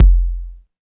118 KICK 3.wav